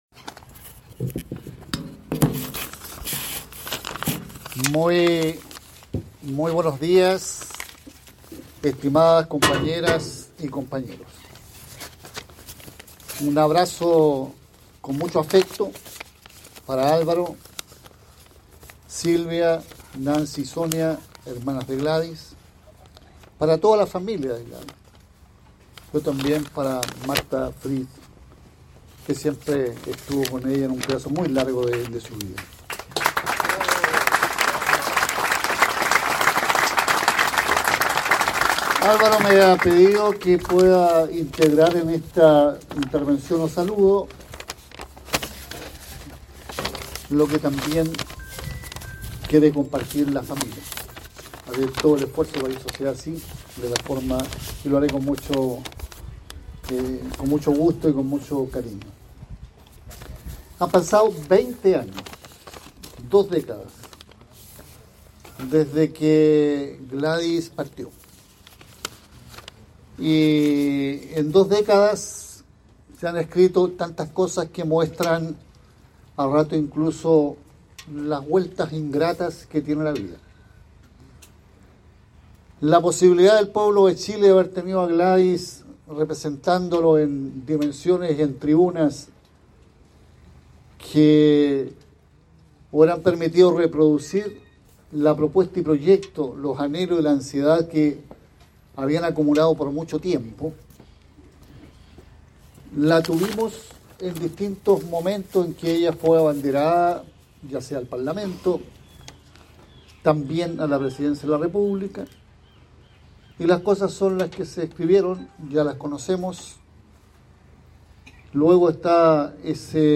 La explanada del Museo se llenó de militantes, amigos y simpatizantes que quisieron ser parte del homenaje a quien fuera presidenta del Partido Comunista de Chile (PCCh). En el marco de los 20 años de su fallecimiento, la presentación del libro se convirtió en un acto de memoria y reafirmación del legado de una de las figuras más emblemáticas de la izquierda chilena.